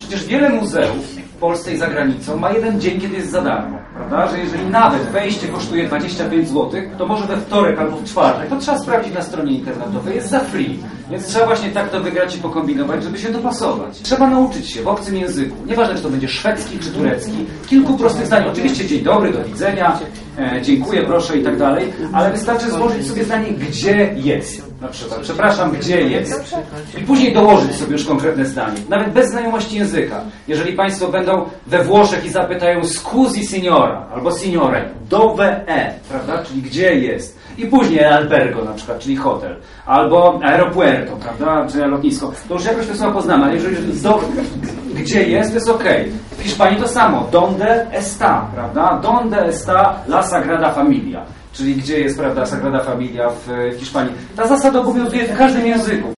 W Miejskiej i Powiatowej Bbiliotece Publicznej zebrało się wielu mieszkańców miasta i okolic by wysłuchać fascynującej opowieści o krajowych i zagranicznych wojażach.
Spotkanie z Jakubem Poradą odbyło się w ramach Klubu Podróżnika działającego przy żnińskiej bibliotece.